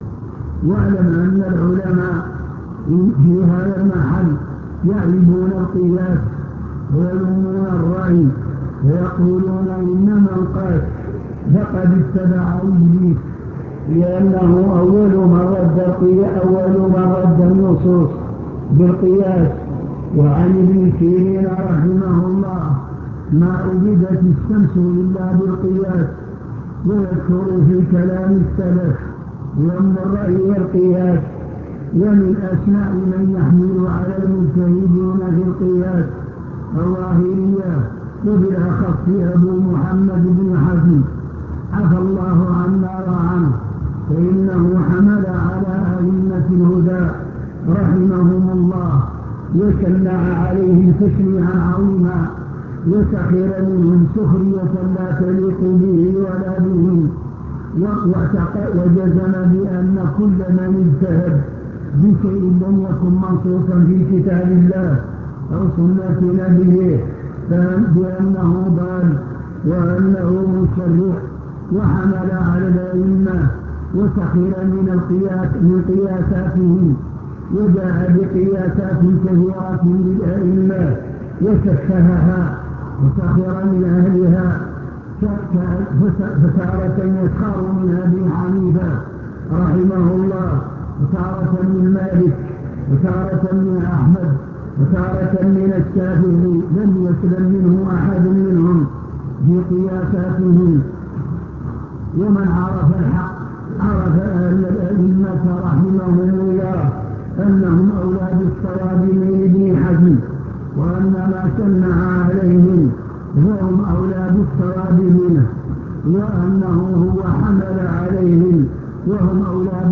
المكتبة الصوتية  تسجيلات - محاضرات ودروس  الرد على ابن حزم